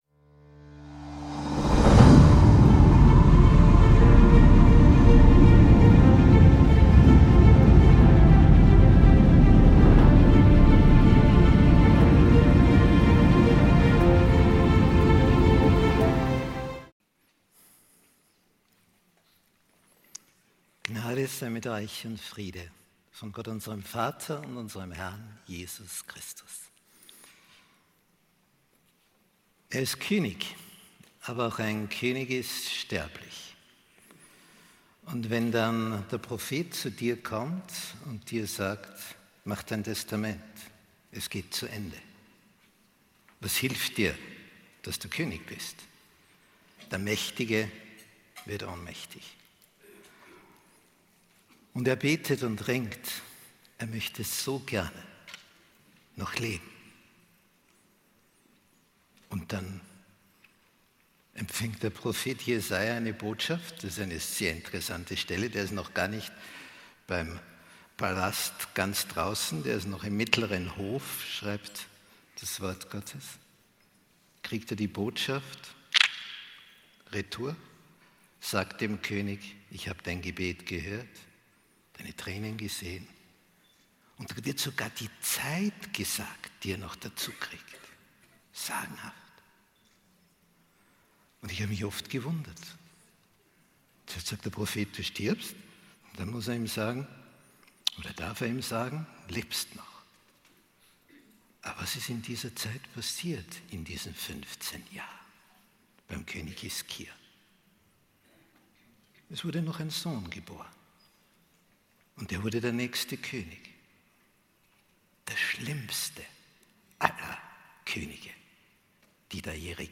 Ein eindringlicher Vortrag über Umkehr, Hoffnung und geistliche Erneuerung, der in der heutigen Zeit Relevanz findet und zum Nachdenken anregt.